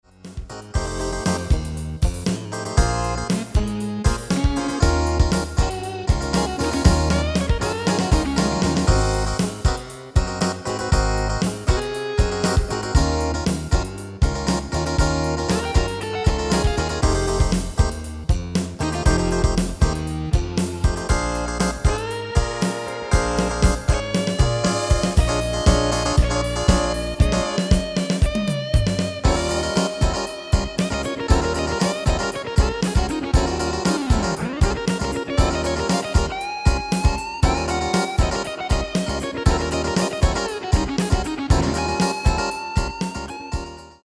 Demo/Koop midifile
Genre: Pop & Rock Internationaal
Toonsoort: Ebm
- Géén vocal harmony tracks